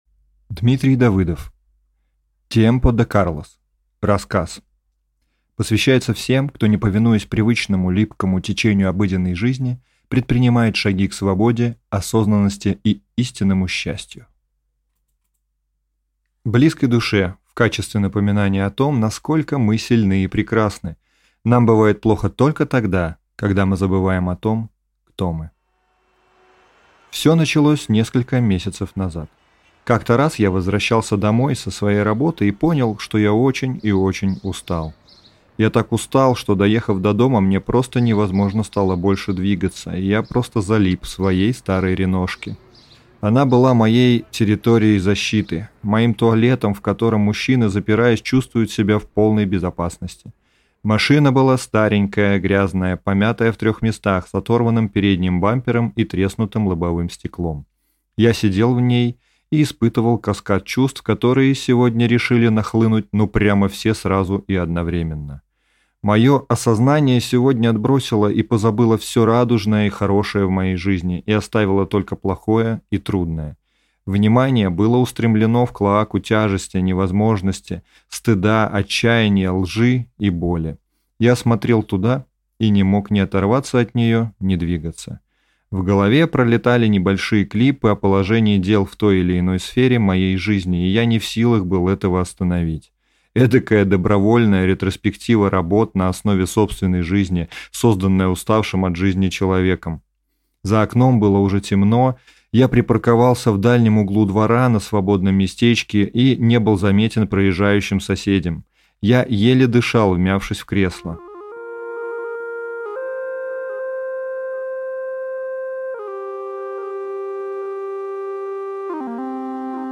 Аудиокнига Tiempo De Carlos | Библиотека аудиокниг